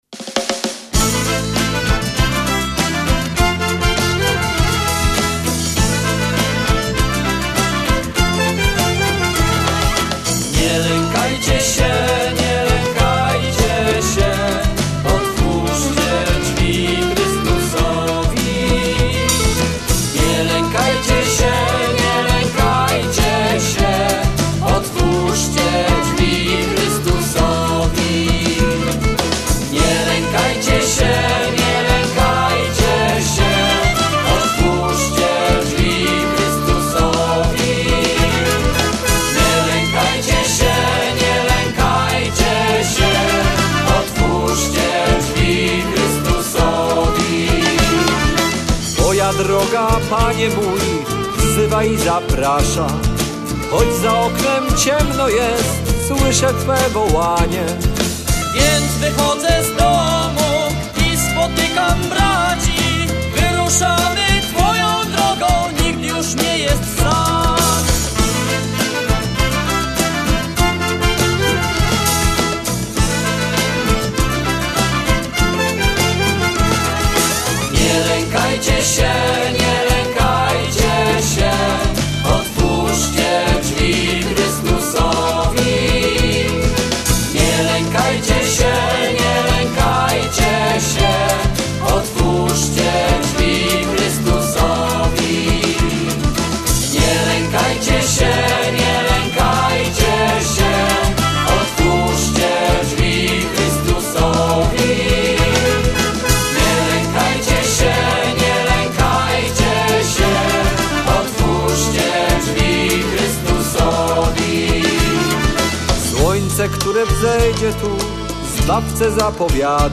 Hymn pielgrzymki: